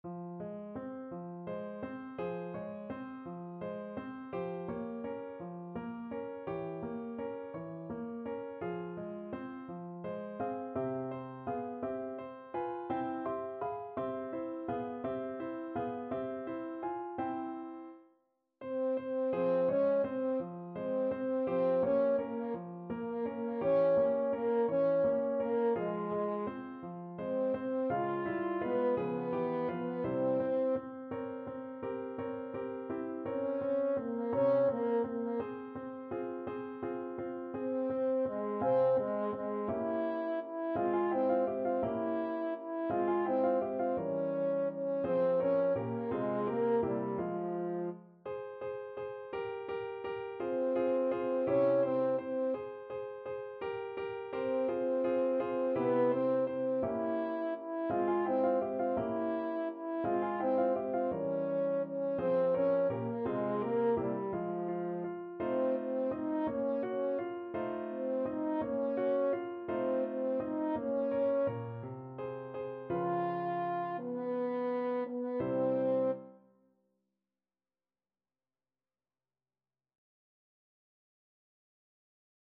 French Horn version
. = 56 Andante
6/8 (View more 6/8 Music)
F4-F5
Classical (View more Classical French Horn Music)